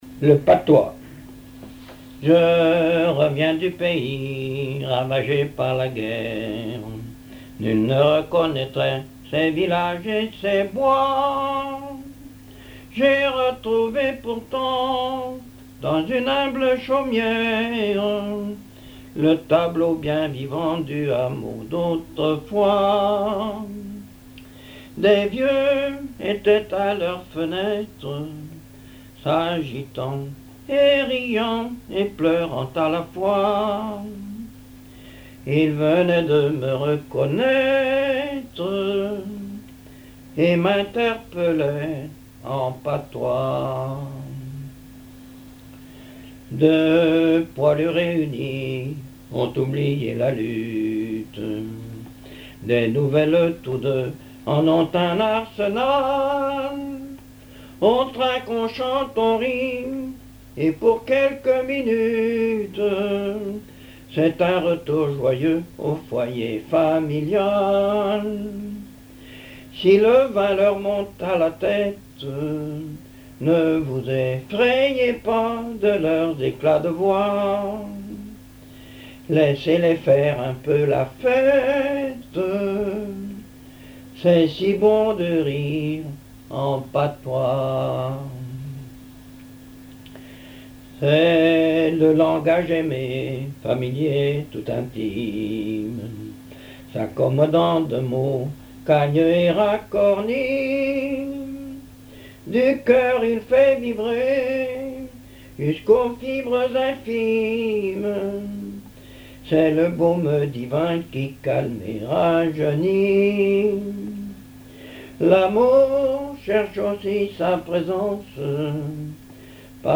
Genre strophique
contes, récits et chansons populaires
Pièce musicale inédite